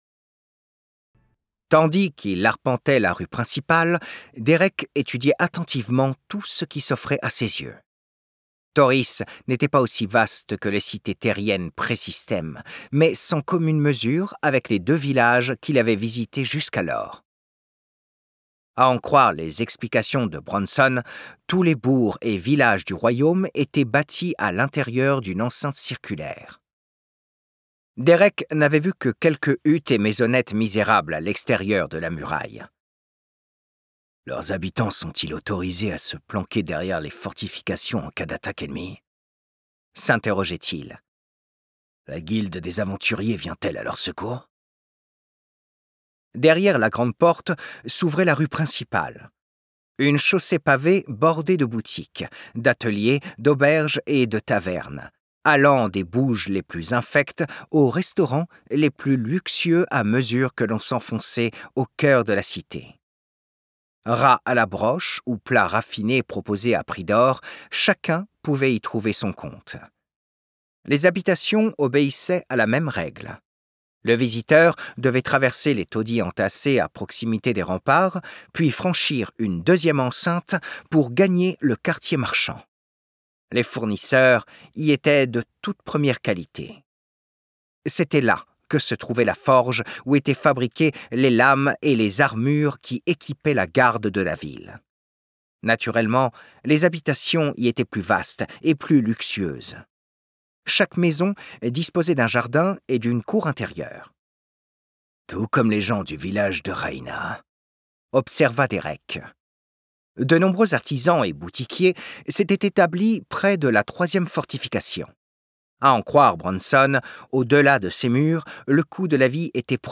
Extrait gratuit
Le deuxième tome de la saga System Universe, accompagné d'enrichissements sonores pour favoriser l'immersion dans ce monde digne des jeux-vidéo !